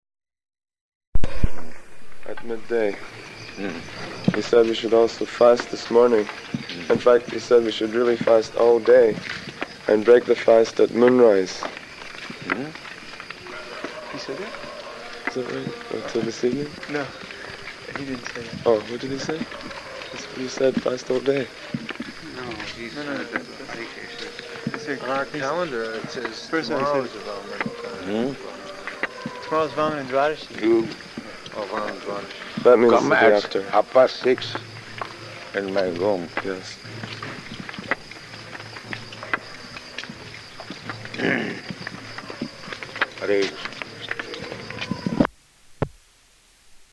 Morning Walk [partially recorded]
Type: Walk
Location: Vṛndāvana